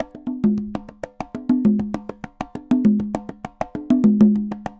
Congas_Candombe 100_2.wav